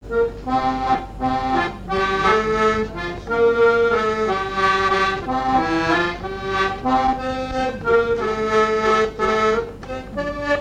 danse : valse
chansons et essais à l'accordéon diatonique
Pièce musicale inédite